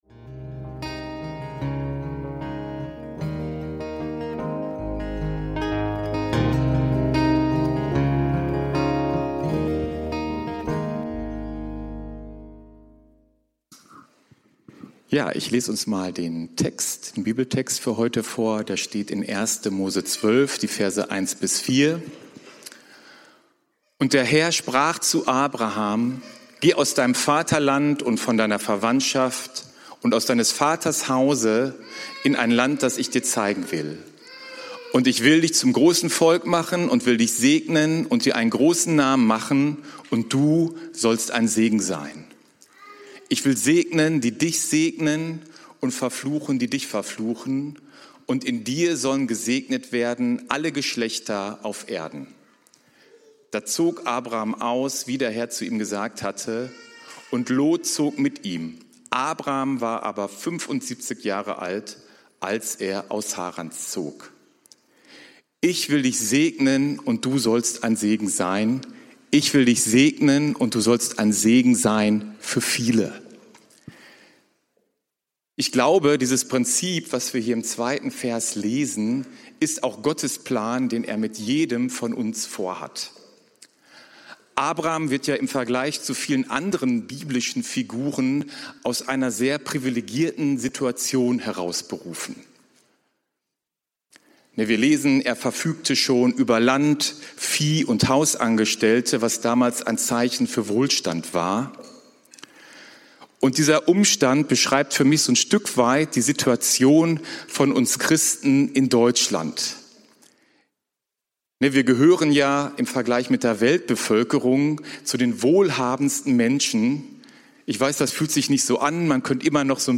Ich will dich segnen und du sollst ein Segen sein – Predigt vom 13.07.2025